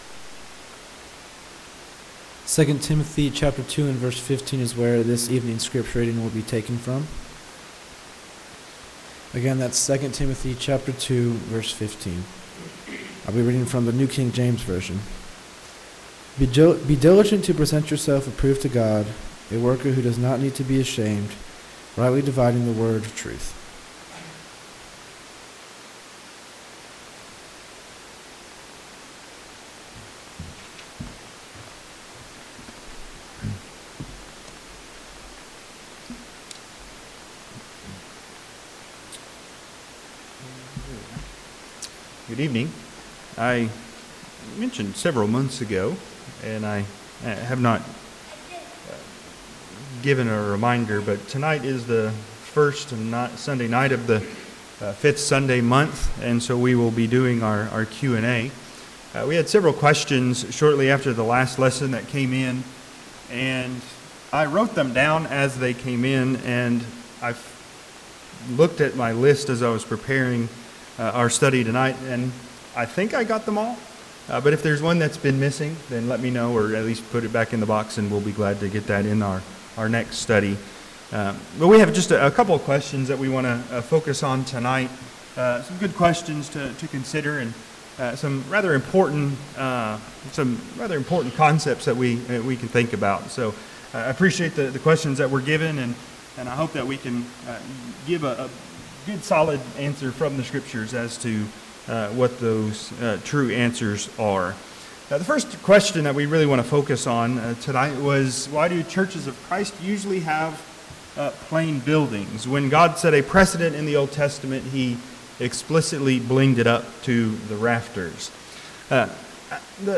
Q&A session that occurs in months with 5-Sundays.